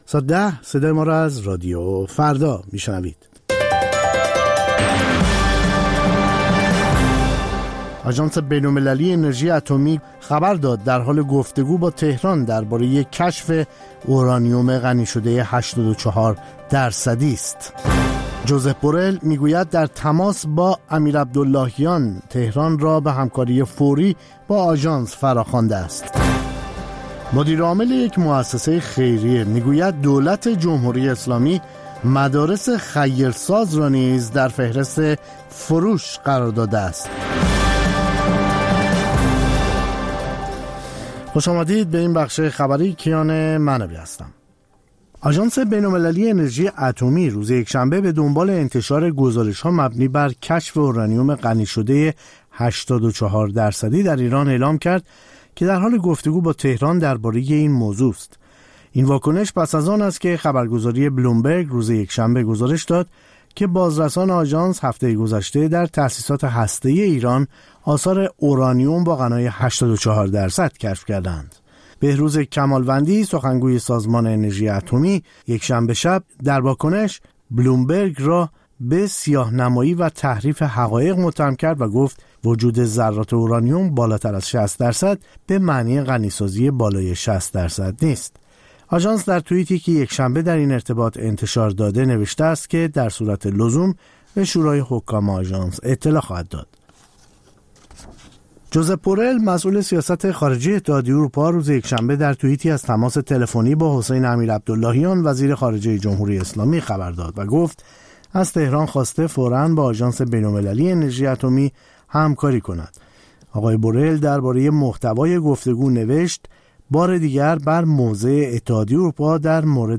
سرخط خبرها ۱۰:۰۰